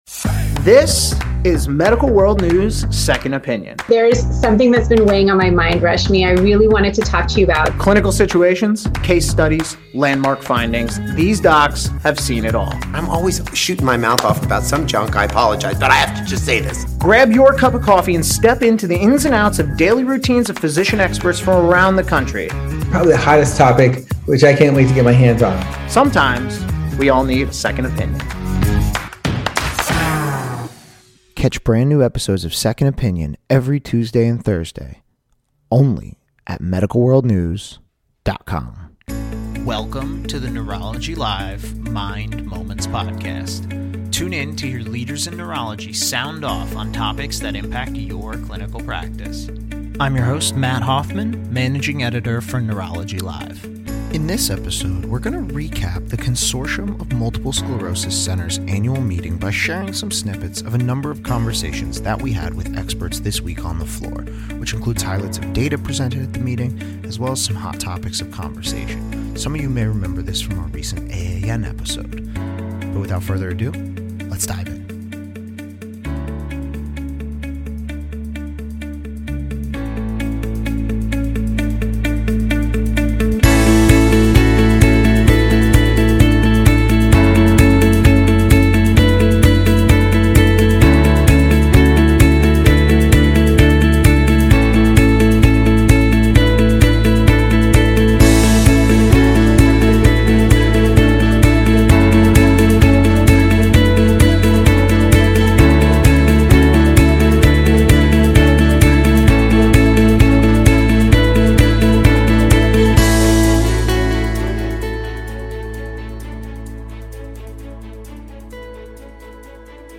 In this episode, we spoke with a number of individuals who presented data and spoke at the Consortium of Multiple Sclerosis Centers Annual Meeting, on topics including women with multiple sclerosis, the importance of measuring cognition in MS, the characterization of relapse attacks in neuromyelitis optical spectrum disorder, the use of spinal cord atrophy as a biomarker of disease progression, the potential to save time reviewing complex patient histories, and more.